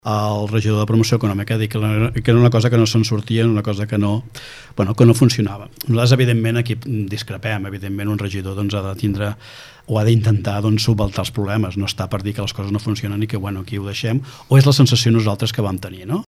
L’alcalde Lluís Puig i el cap de l’oposició Josep Coll debaten el present i futur del municipi entre discrepàncies i acords